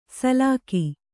♪ salāki